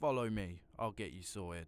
Follow me ill get you sorted.wav